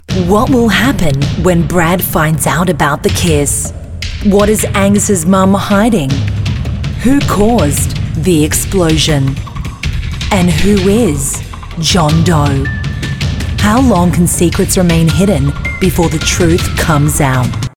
Female
Television Spots
Tv, Web, Id Imaging